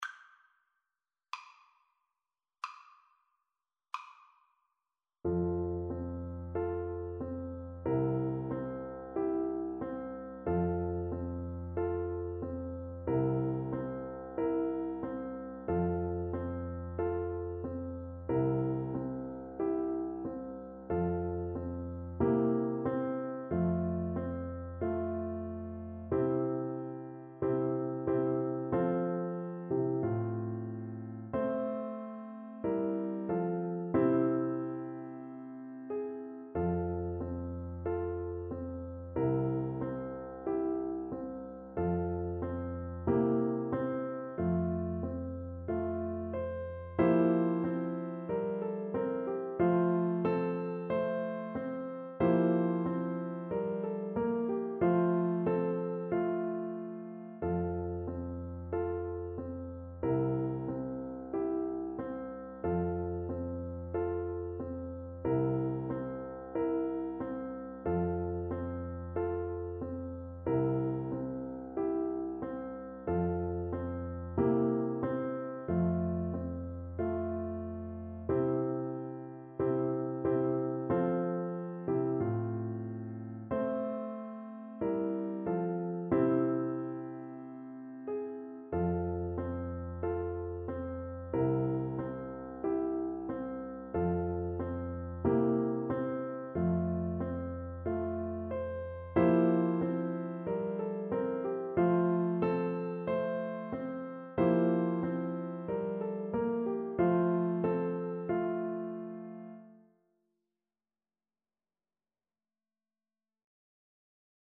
Play (or use space bar on your keyboard) Pause Music Playalong - Piano Accompaniment Playalong Band Accompaniment not yet available transpose reset tempo print settings full screen
French Horn
4/4 (View more 4/4 Music)
F major (Sounding Pitch) C major (French Horn in F) (View more F major Music for French Horn )
Classical (View more Classical French Horn Music)